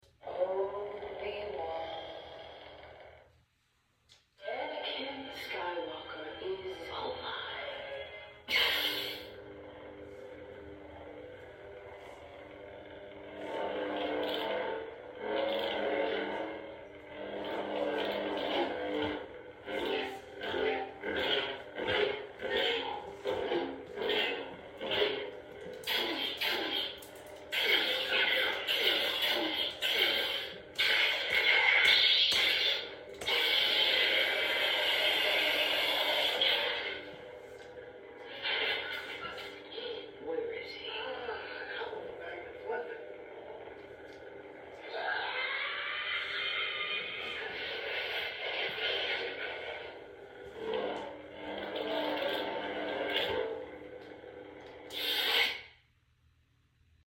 Third Sister Lightsaber Sound Font